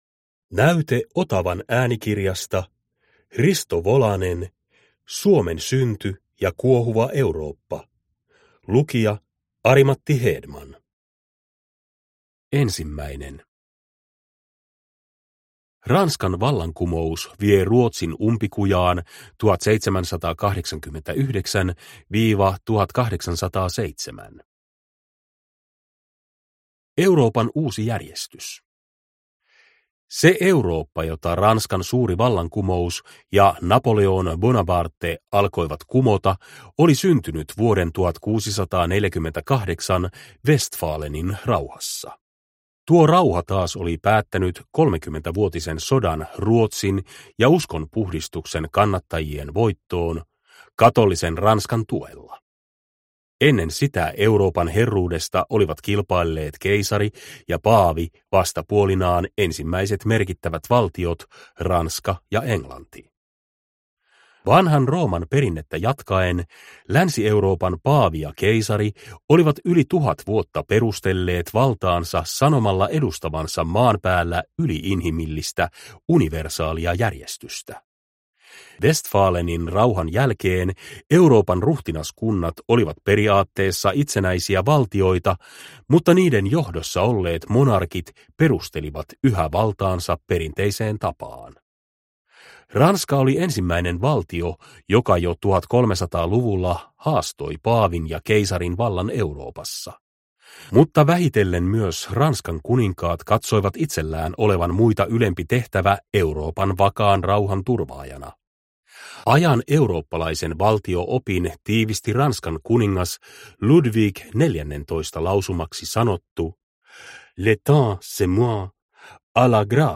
Suomen synty ja kuohuva Eurooppa – Ljudbok – Laddas ner